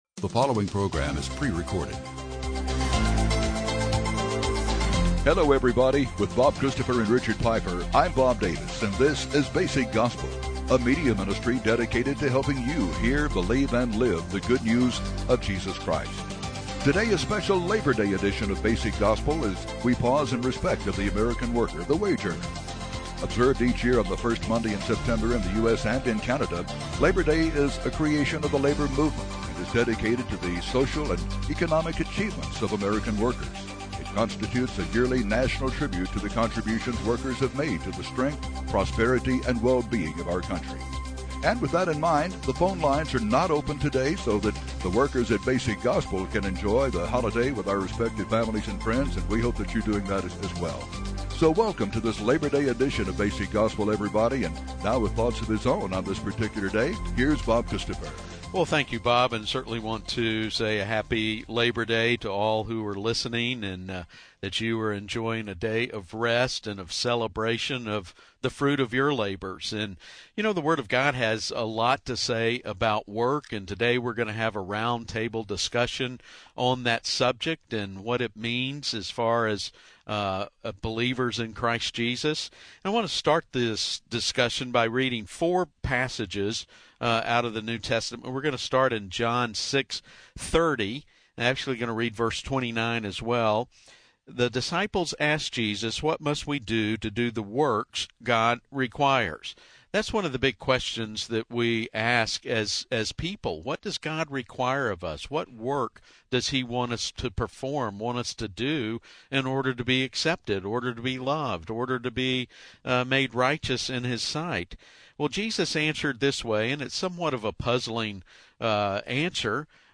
A special Labor Day broadcast! The Word of God has a lot to say about work. Today we take a look at just a few passages that speak to work.